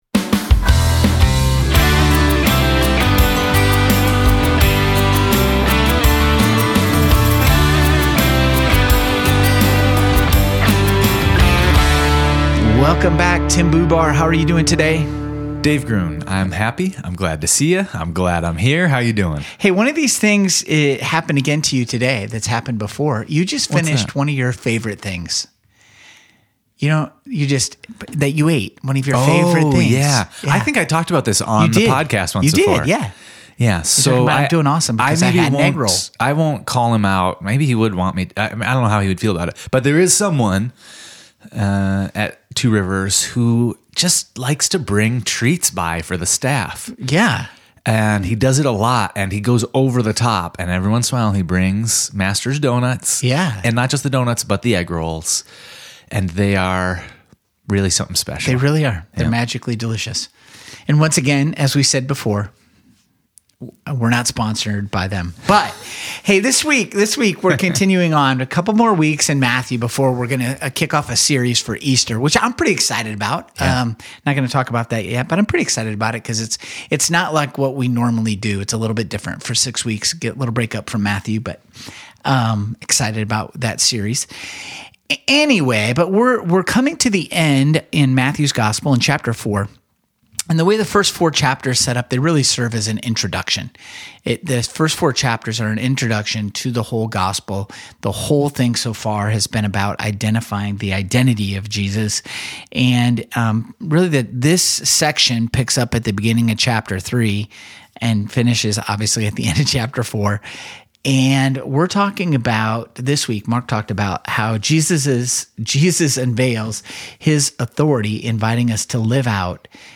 Sermons from Two rivers Church in Knoxville, TN